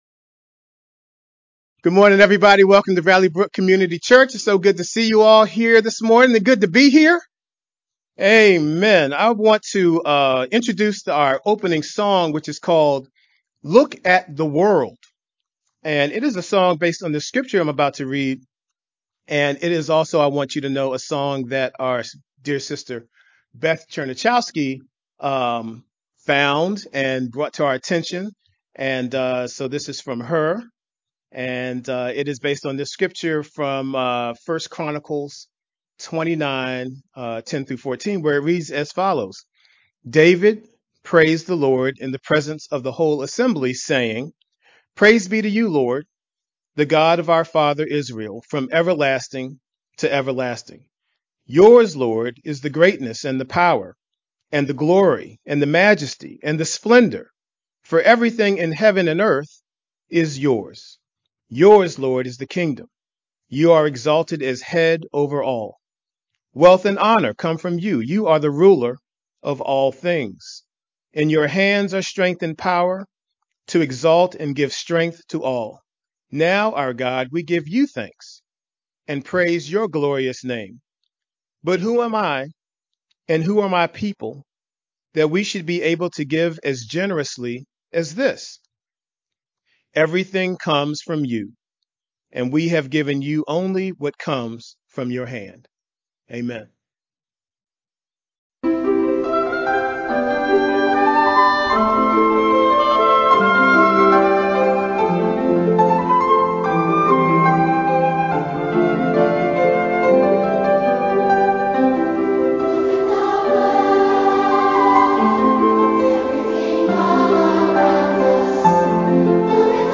July3rd-Sermon-edited-Mp3-CD.mp3